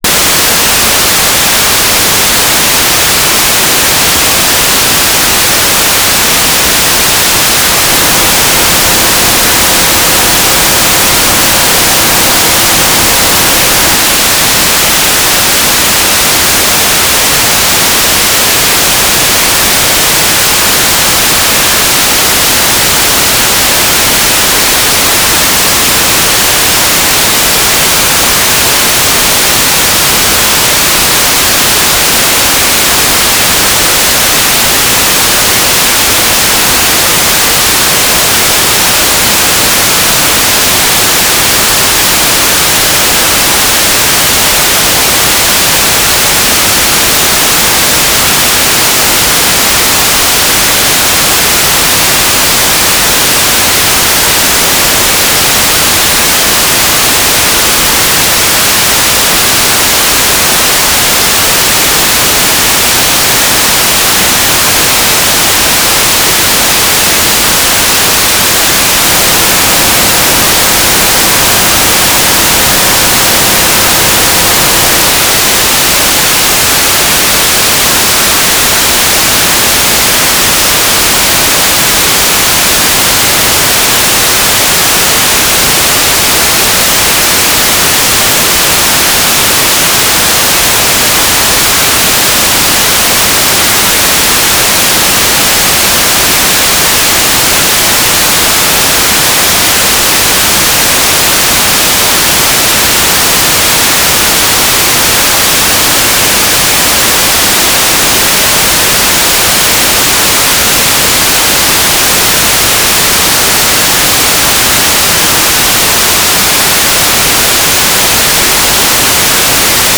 carrier wave